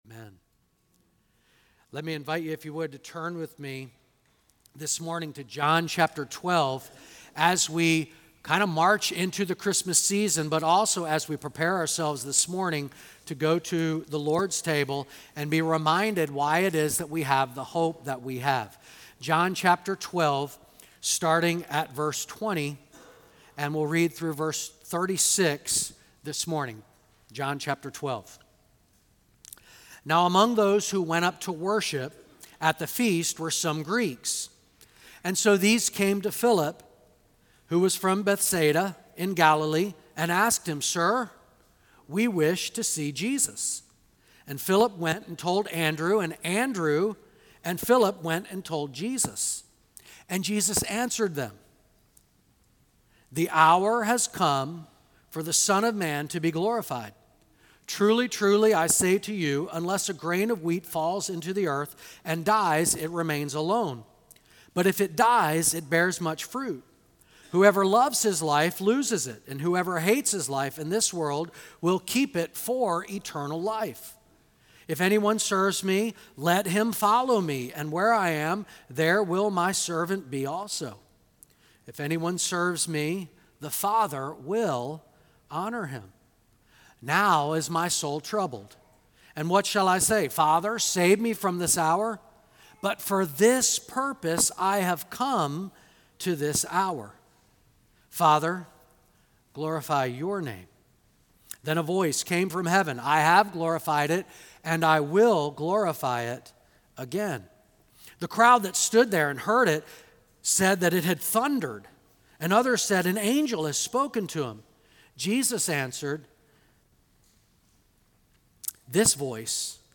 Download Download Sermon Notes December 1, 2024 - The Gospel of John.pdf The Gospel of John The Gospel of John: Signs, Witnesses, & Belief.